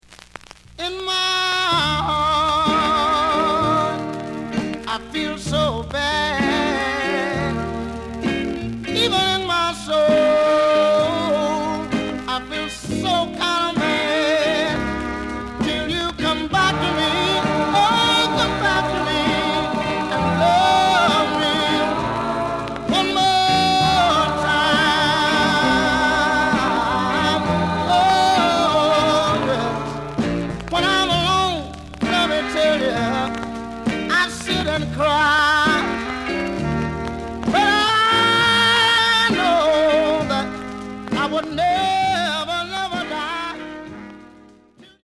The audio sample is recorded from the actual item.
●Genre: Rhythm And Blues / Rock 'n' Roll
Noticeable noise on both sides due to scratches.)